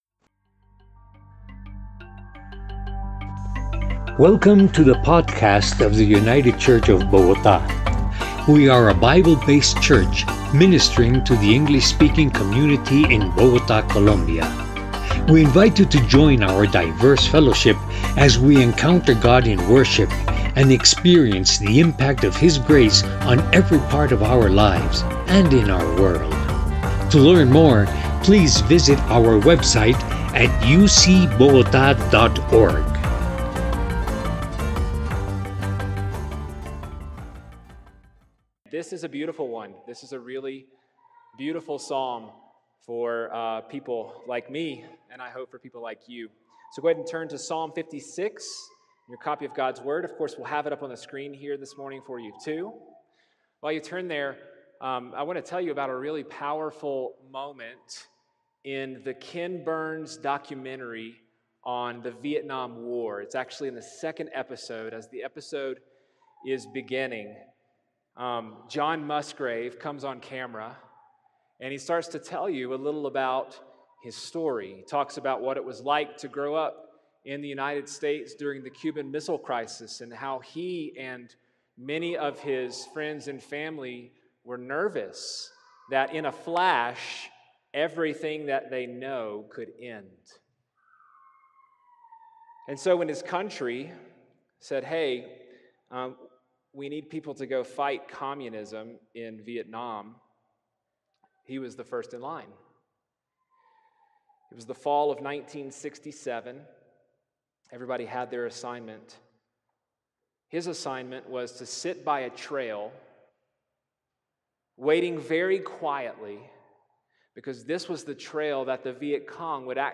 By United Church of Bogotá | 2021-07-18T15:47:46-05:00 July 18th, 2021 | Categories: Sermons | Tags: Summer Psalms | Comments Off on From Fear to Faith Share This Story, Choose Your Platform!